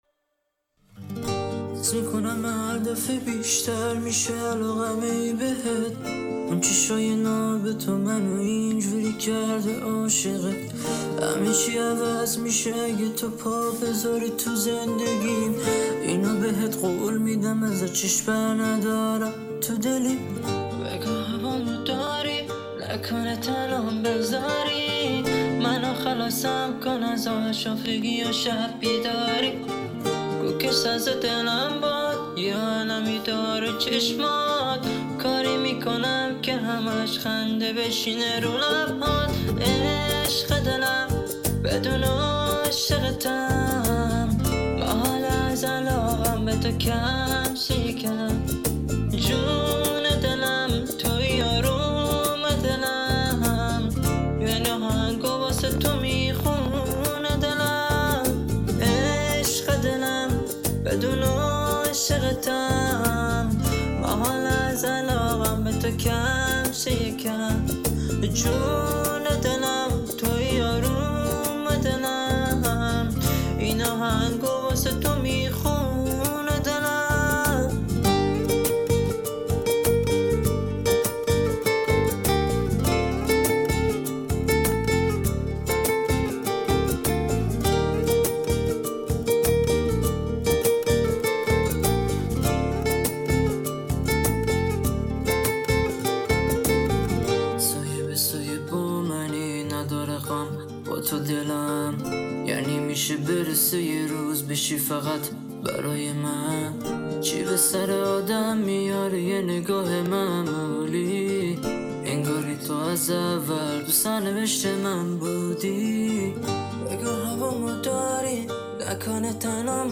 (GUITAR VERSION)